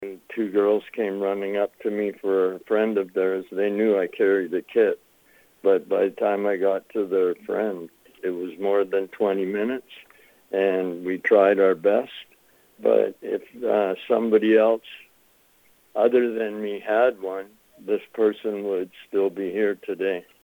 a former addict who carries a naloxone kit and now works in an outreach capacity